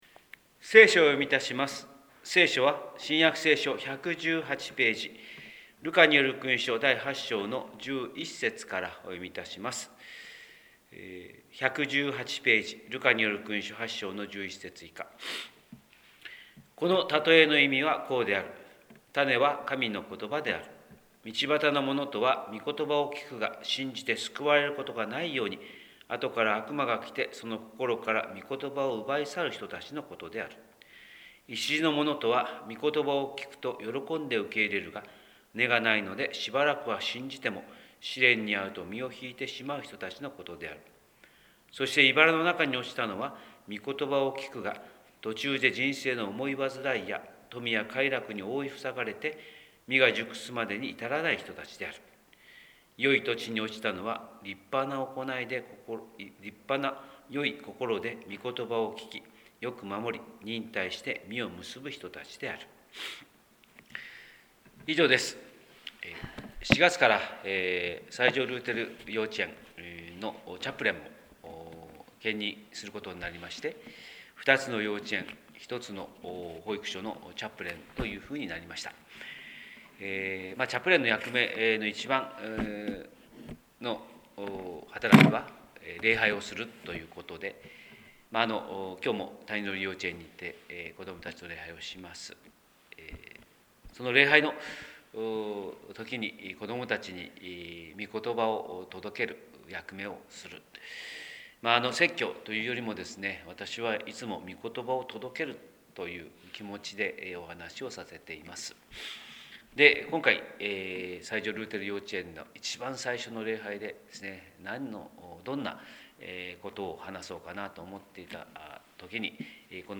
神様の色鉛筆（音声説教）: 広島教会朝礼拝250425